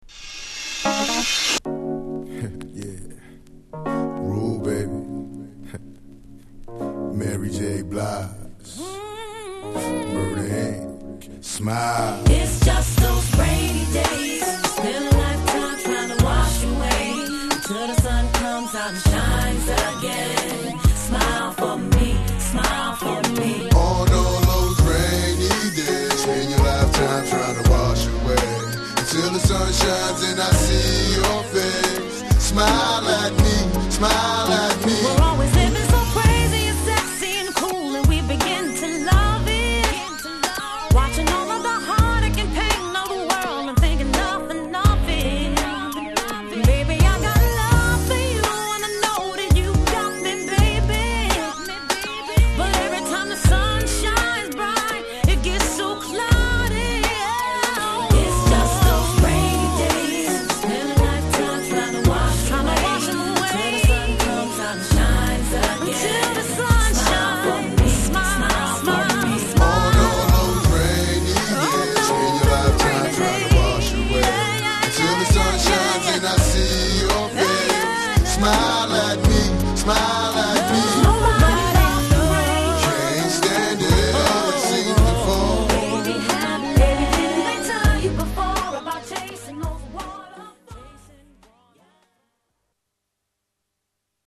Genre: #R&B